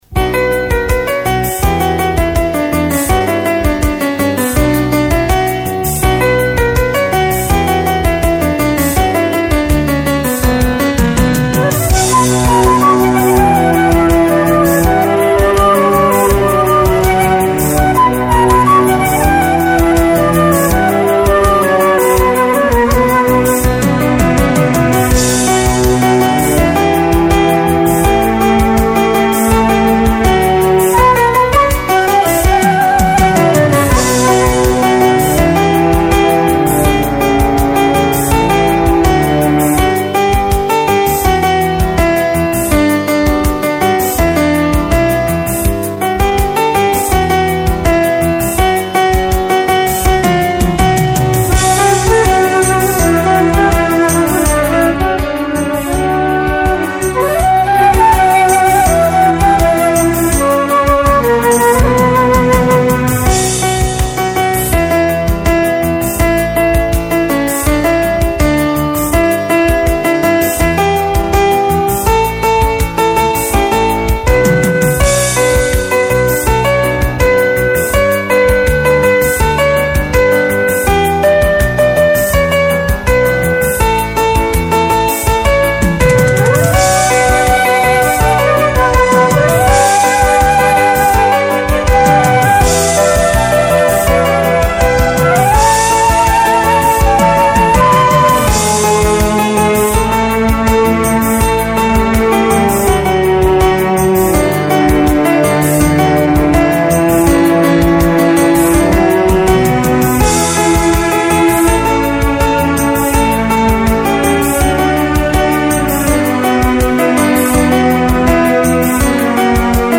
سرودهای روز معلم
بی‌کلام